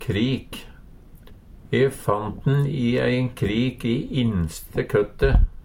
krik - Numedalsmål (en-US)